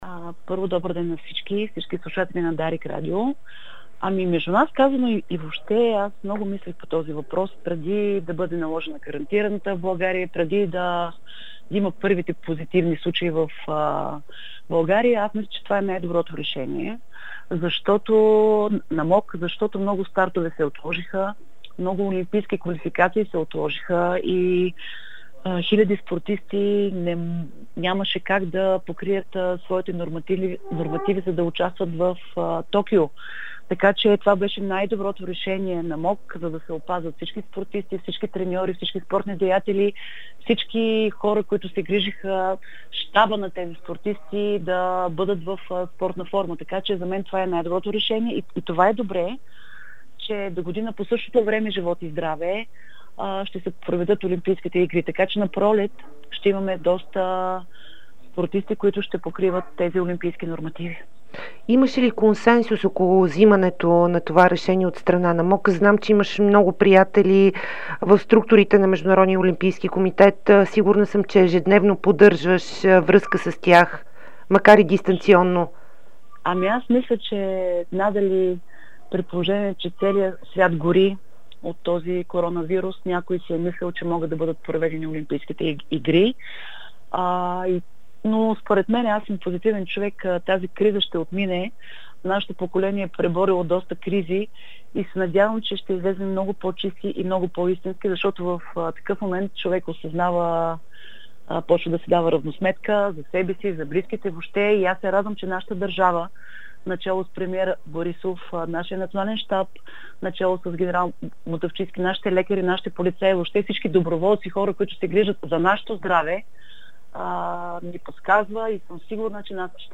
Председателят на Българския олимпийски комитет Стефка Костадинова даде специално интервю за Спортното шоу на Дарик и dsport. В него световната рекордьорка в скок височина сподели мнението си за отлагането на Олимпийските игри с една година и коментира темата с коронавируса.